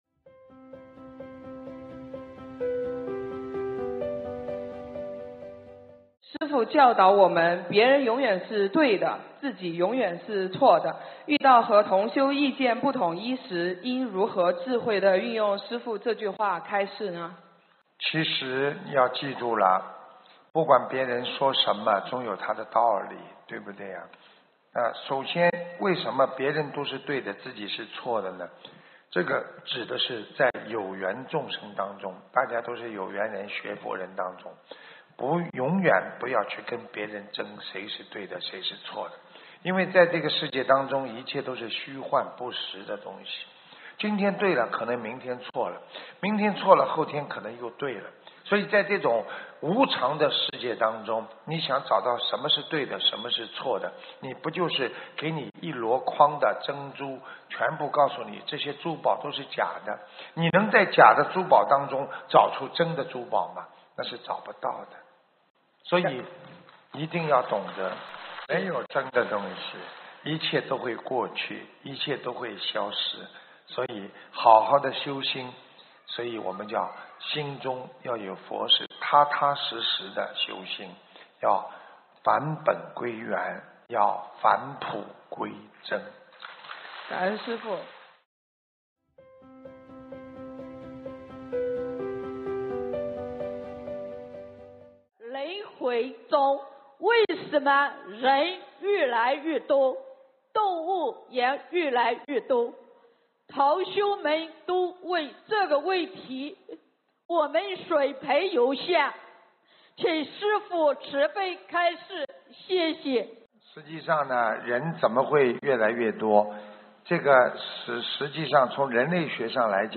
音频：如何理解别人永远是对的！自己永远是错的！2017年2月25日中国澳门世界佛友见面会共修组提问！